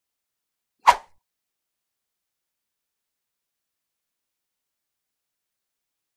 Sword: Single Swish; Single Very Fast, High Pitched Swish. Very Close Perspective. Whoosh.